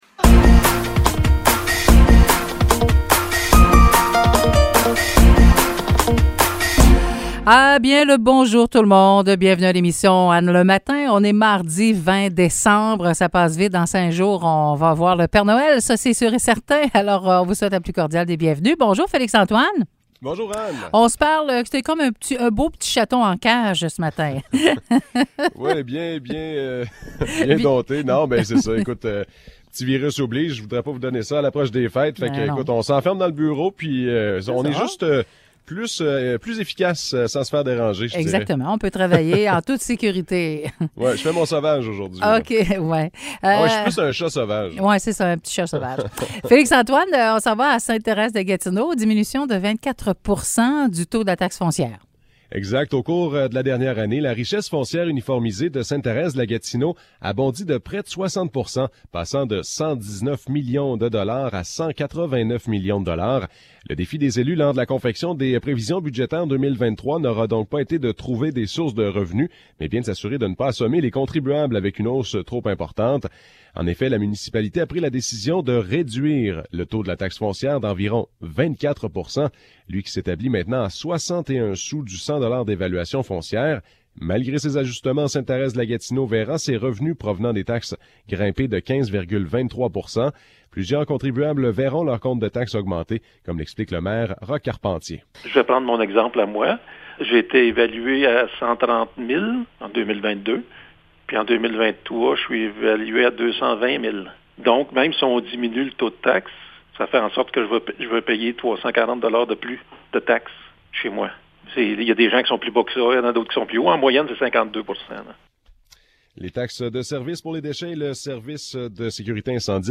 Nouvelles locales - 20 décembre 2022 - 9 h